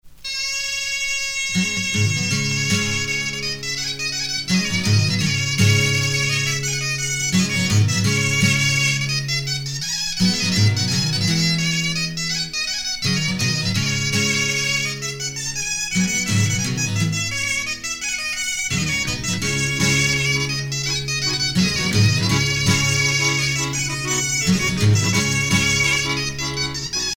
danse : fisel (bretagne)